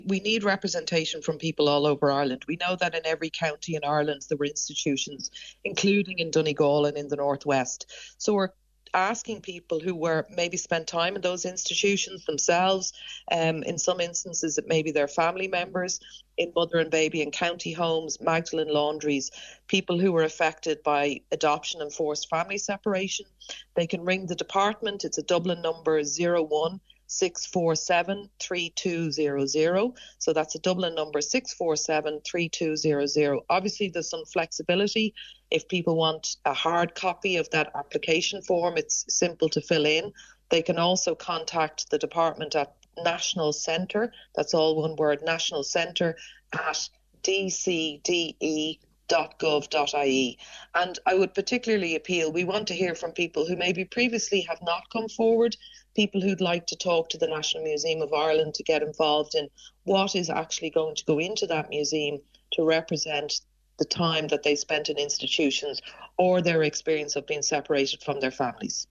With the deadline for applications this Friday, Patricia Carey, Special Advocate for Survivors of Institutional Abuse, is appealing to people in Donegal to come forward: